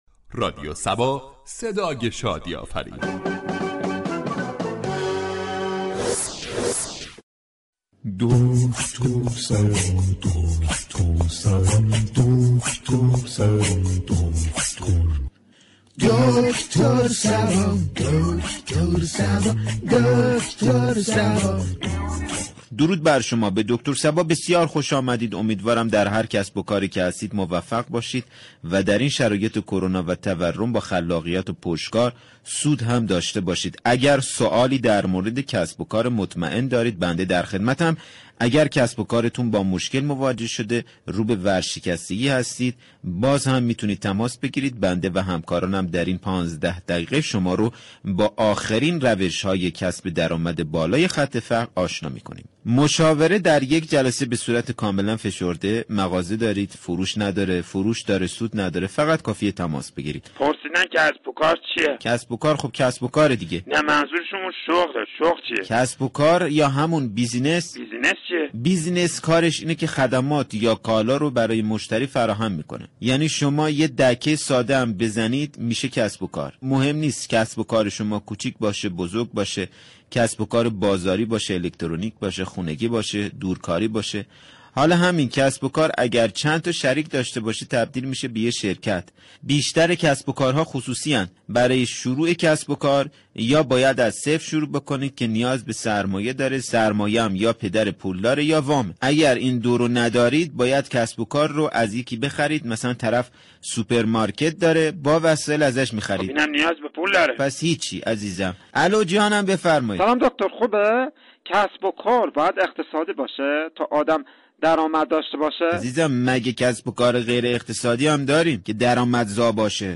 برنامه طنز "دكتر صبا " با بیان مسایل اجتماعی و فرهنگی با نگاهی طنز برای مخاطبان نسخه شادی و لبخند می پیچید.